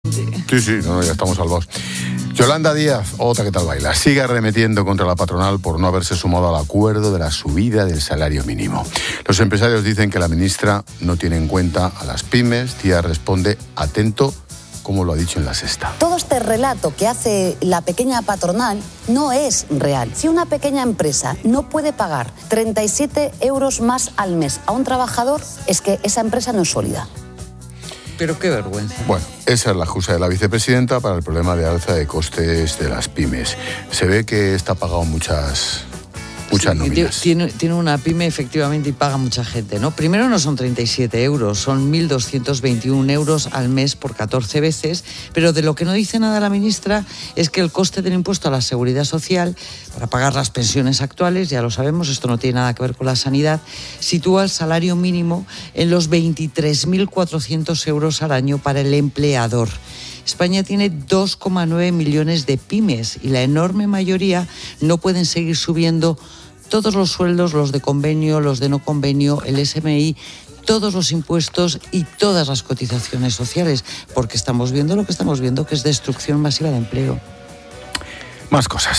La economista Pilar García de la Granja desmonta en 'La Linterna' el argumento de la vicepresidenta sobre el impacto de la subida del SMI en las pequeñas empresas
Expósito aprende en Clases de Economía de La Linterna con la experta económica y directora de Mediodía COPE, Pilar García de la Granja, sobre las declaraciones de Yolanda Díaz sobre el salario mínimo y las pymes